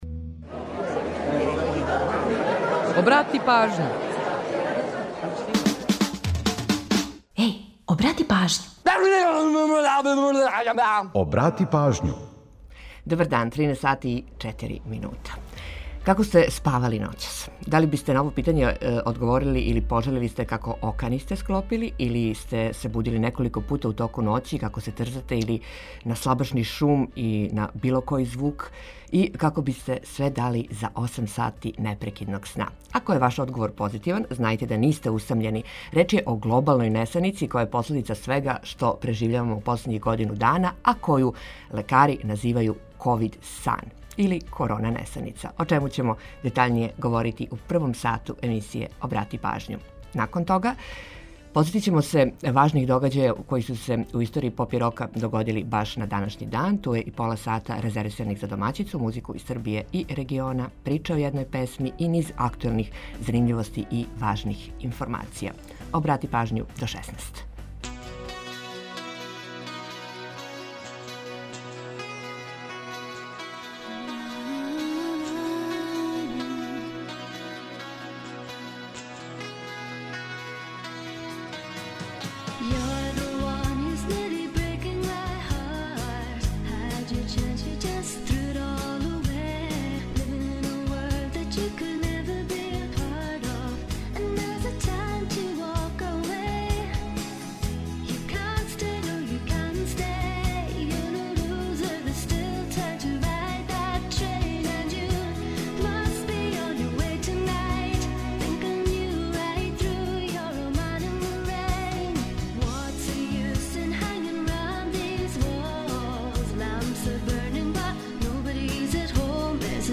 Ако је ваш одговор позитиван, знајте да нисте усамљени, реч је глобалној несаници која је последица свега што преживљавамо, а коју називају „ковид сан”, о чему ћемо детаљније у емисији. У наставку емисије подсетићемо вас на важне догађаје у поп рок историји који су догодили на данашњи дан. Ту је и пола сата резервисаних за домаћицу, музику из Србије и региона, прича о једној песми и низ актуелних занимљивости и важних информација.